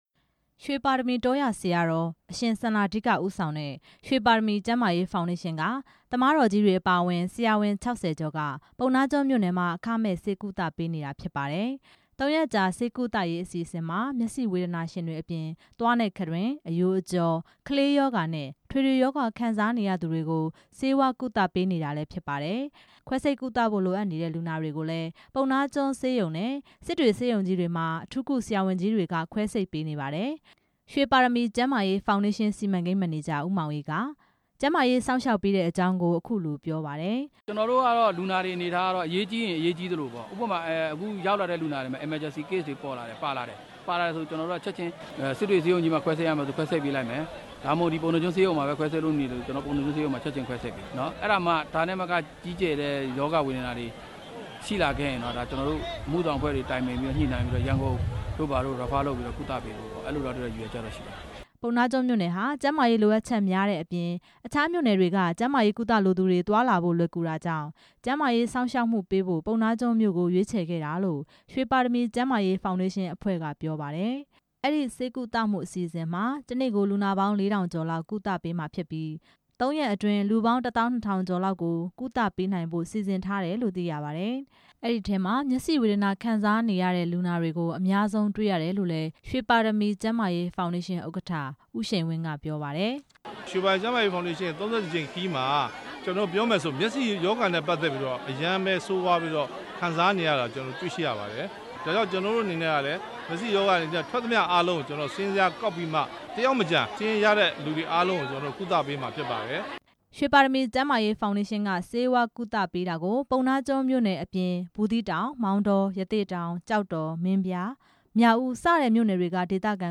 ပုဏ္ဏားကျွန်းမြို့ကနေ ပေးပို့ထားပြီး